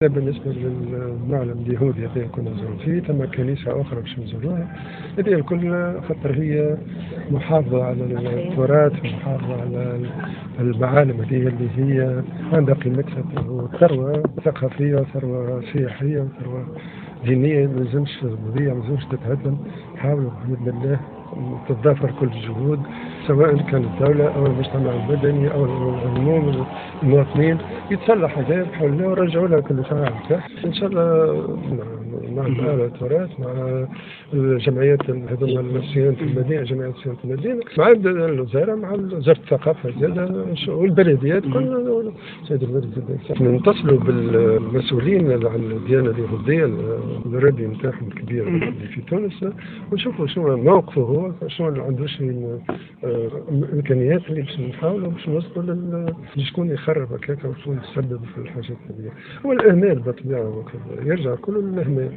وأفاد في تصريح لـ "الجوهرة أف أم" أنه سيقع الاتصال بكبير أحبار اليهود للوقوف على الأسباب وراء إهمال هذا الكنيس وتخريبه.